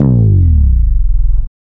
Bass_Stab_09.wav